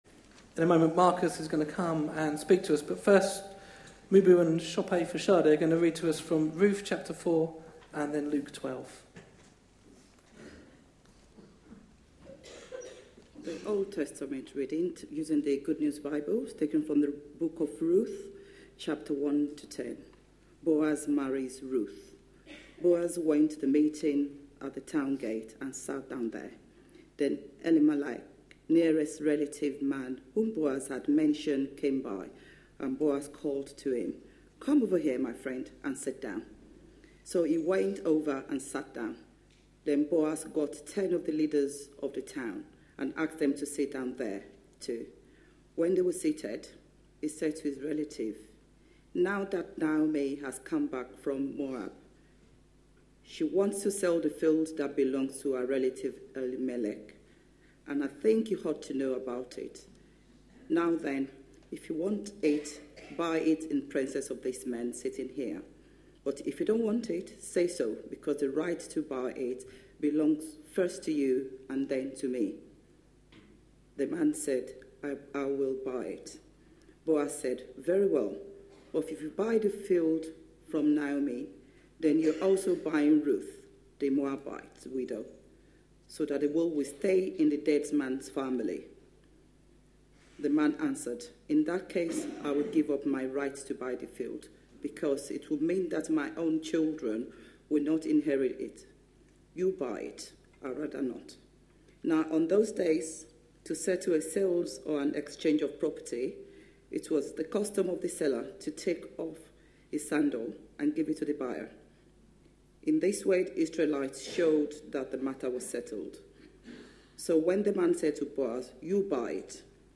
A sermon preached on 31st January, 2016.